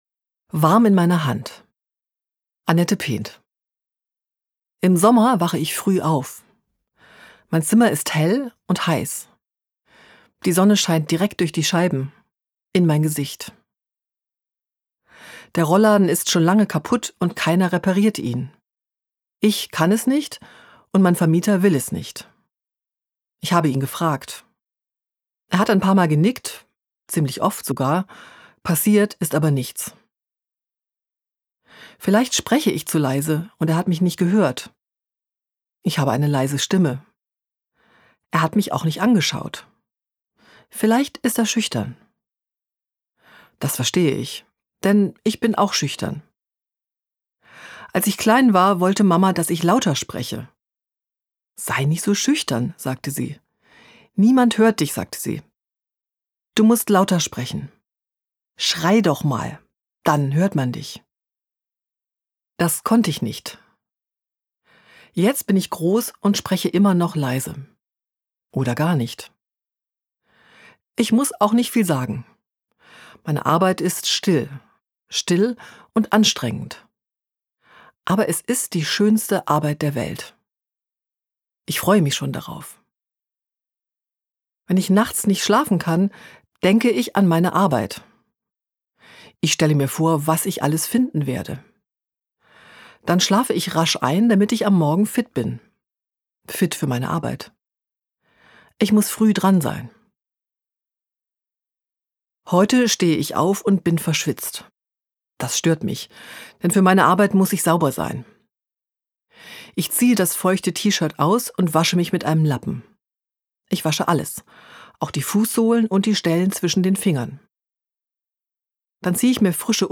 LiES. Das zweite Hörbuch